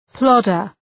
Προφορά
{‘plɒdər}